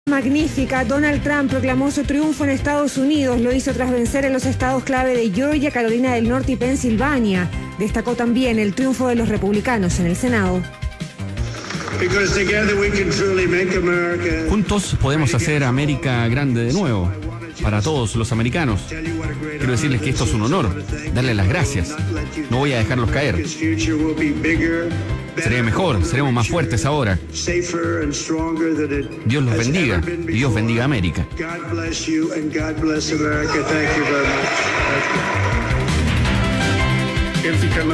Durante un discurso en Florida, rodeado de su familia y de su compañero de fórmula, el senador JD Vance, Trump celebró lo que denominó una “magnífica victoria”. “Es una victoria para el pueblo estadounidense que nos permitirá hacer de nuevo grande a Estados Unidos”, afirmó ante una multitud de seguidores entusiastas.